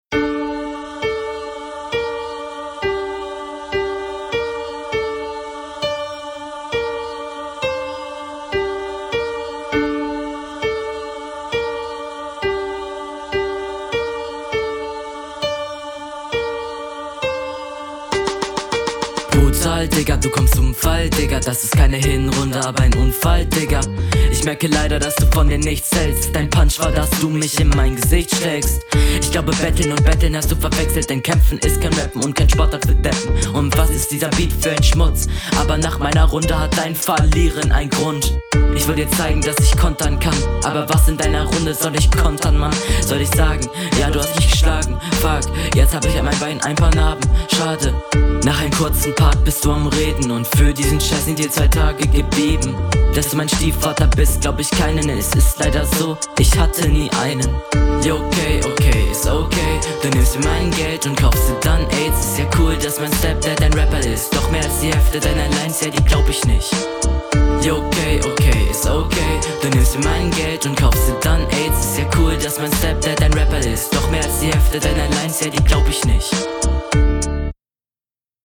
Finde die Qualität schon viel Besser und die Punchlines sind mehr als solide ⠀⠀⠀⠀⠀⠀⠀⠀⠀⠀⠀⠀⠀⠀⠀⠀⠀⠀⠀⠀⠀⠀⠀⠀⠀⠀⠀⠀⠀⠀⠀⠀⠀⠀⠀